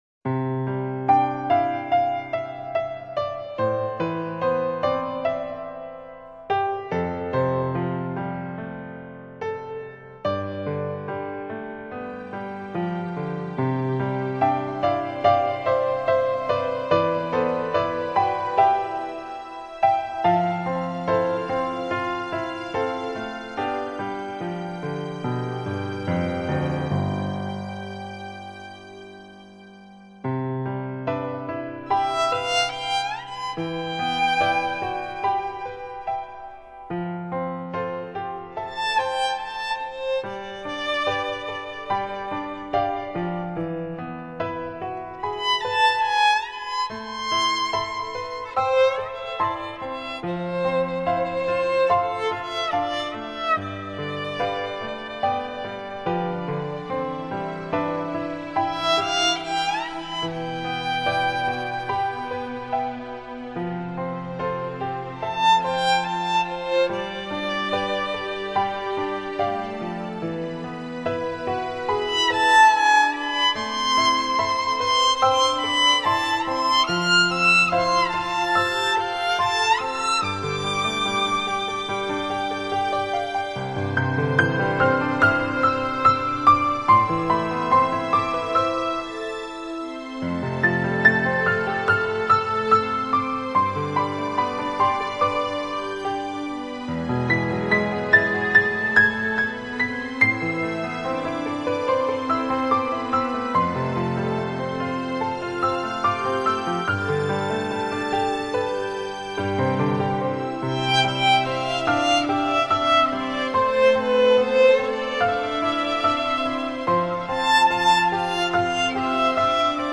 ◆音乐类型：纯音乐
听，黑管与长笛缠绕缱绻如丝的爱意。
听，大提琴与小提琴合鸣凝神的倾听。